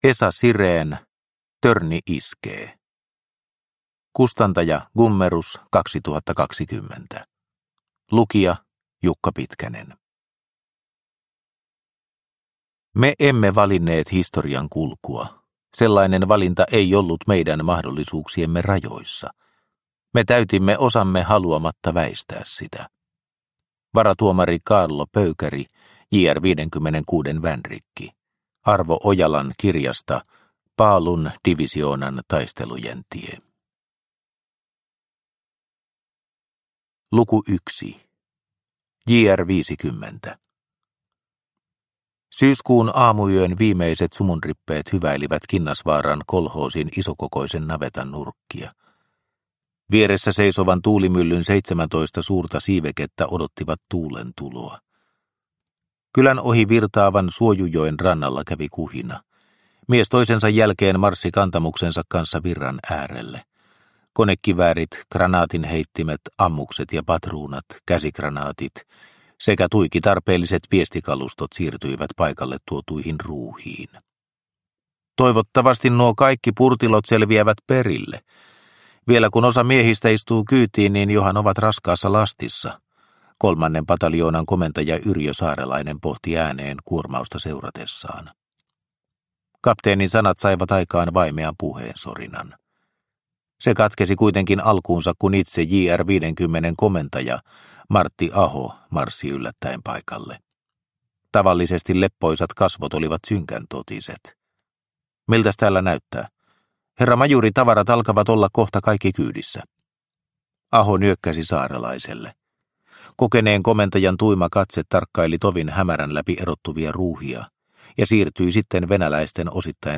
Törni iskee – Ljudbok – Laddas ner